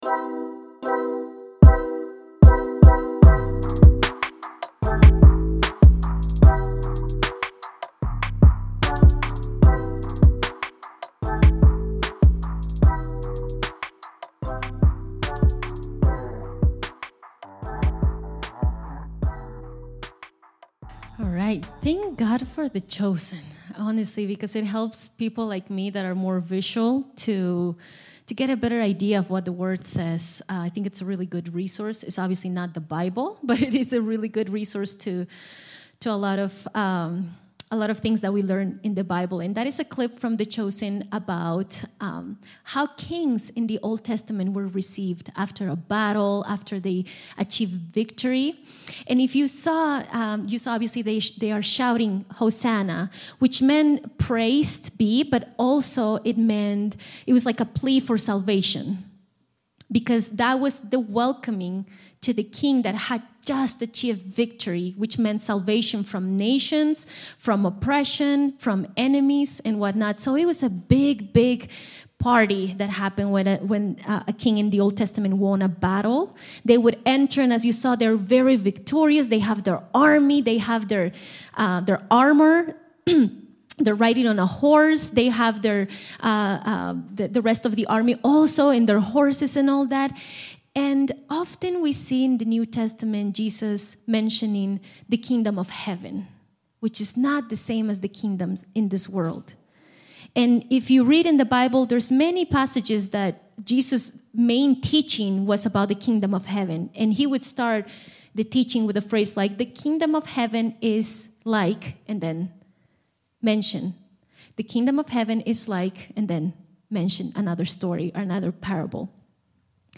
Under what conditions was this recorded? Revelation 2:2-5 Service Type: Sunday Service